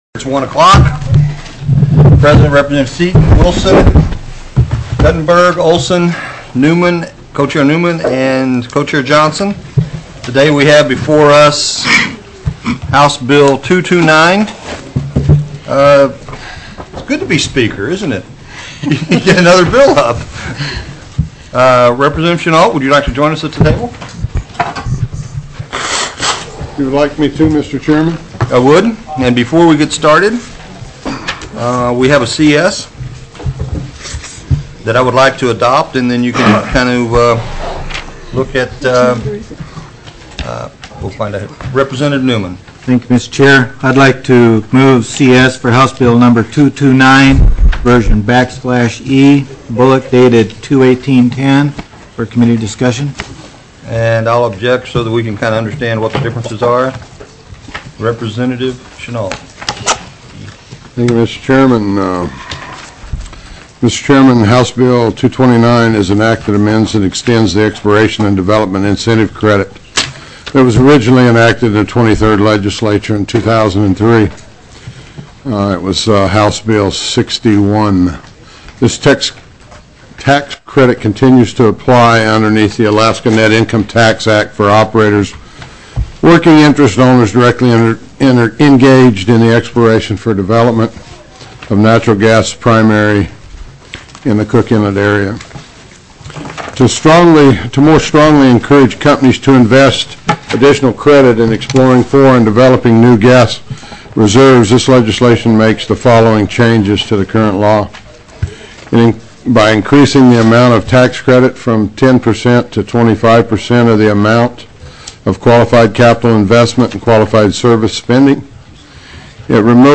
03/15/2010 01:00 PM House RESOURCES
Representative Chris Tuck (via teleconference)